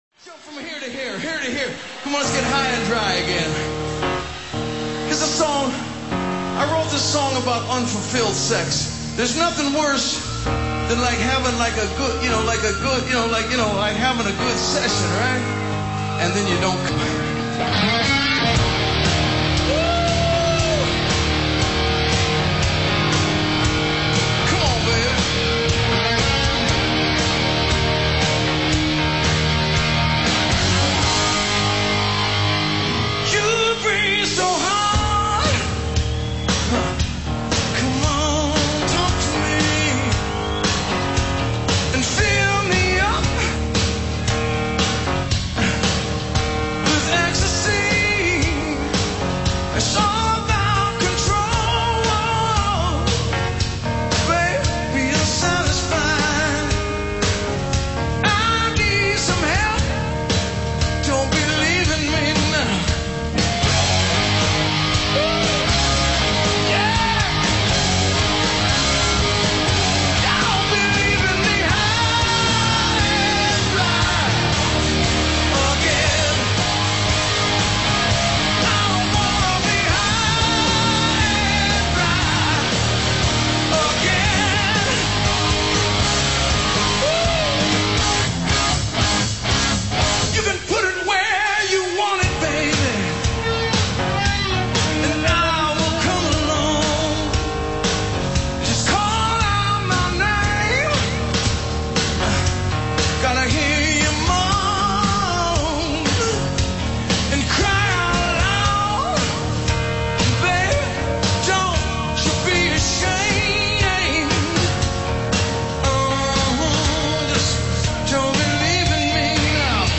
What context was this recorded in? "In Concert" Live at the Universal Amphitheatre - Los Angeles